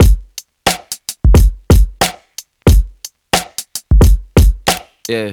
• 90 Bpm HQ Drum Beat D Key.wav
Free drum loop sample - kick tuned to the D note.
90-bpm-hq-drum-beat-d-key-FKZ.wav